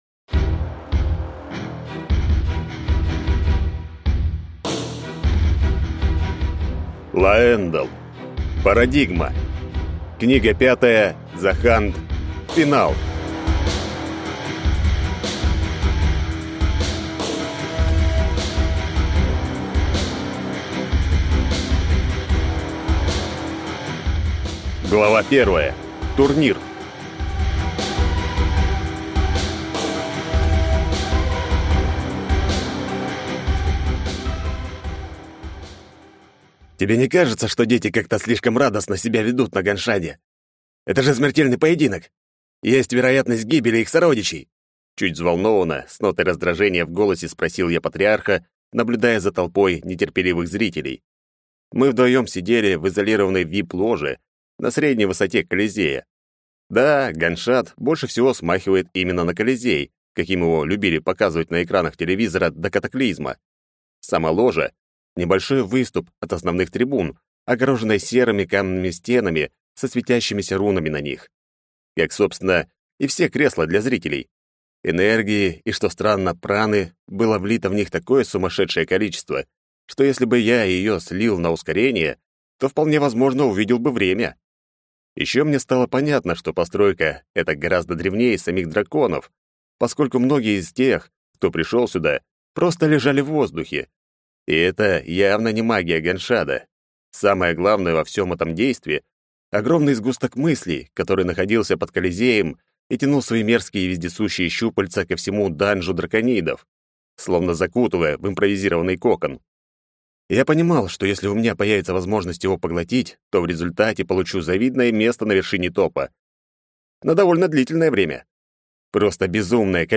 Аудиокнига Заханд. Финал | Библиотека аудиокниг